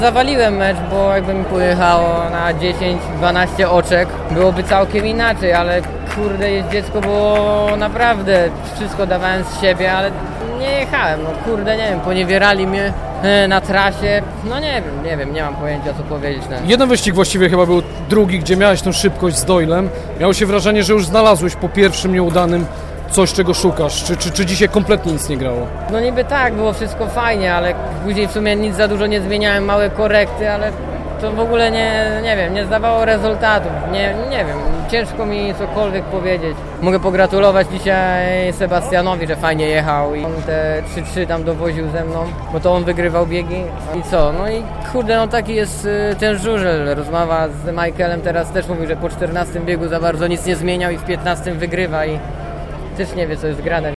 Posłuchajmy pomeczowej wypowiedzi Patryka Dudka: